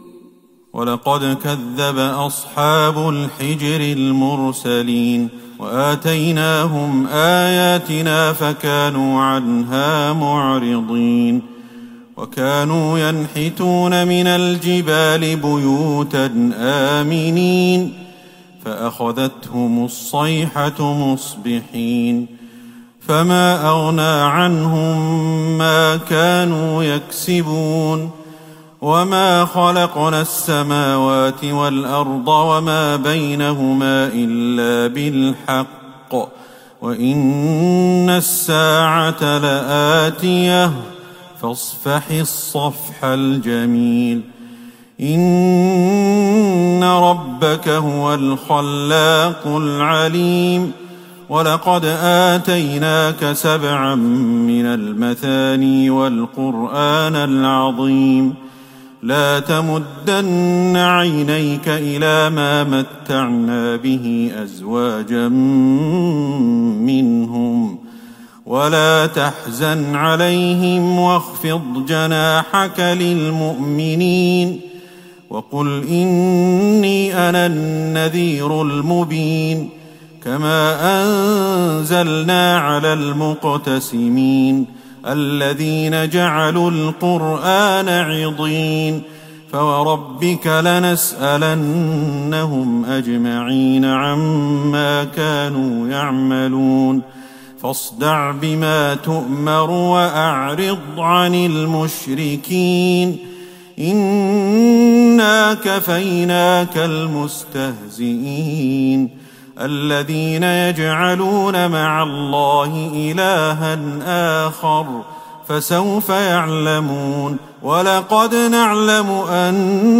ليلة ١٨ رمضان ١٤٤١هـ من سورة الحجر { ٨٠-٩٩ } والنحل { ١-٥٢ } > تراويح الحرم النبوي عام 1441 🕌 > التراويح - تلاوات الحرمين